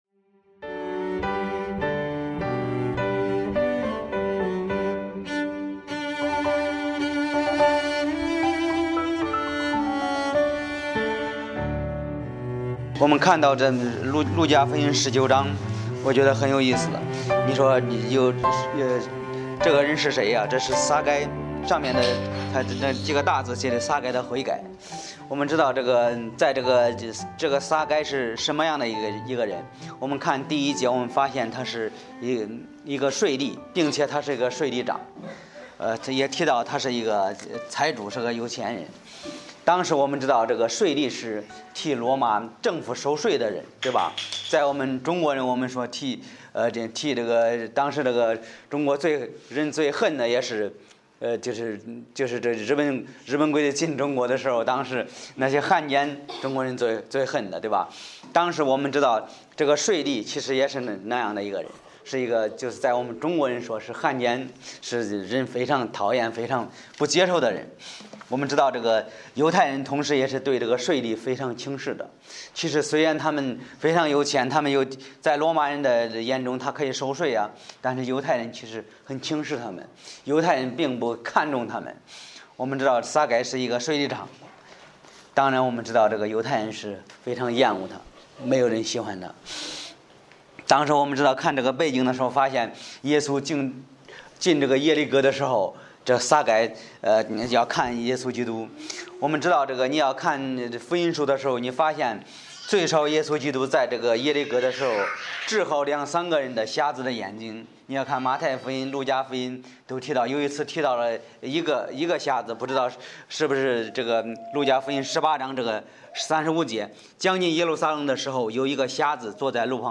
Bible Text: 路加福音19：1-10 | 讲道者